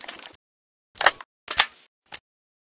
ak74_grenload.ogg